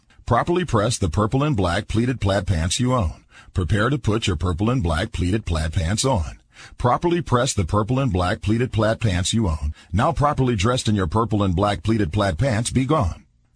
tongue_twister_06_01.mp3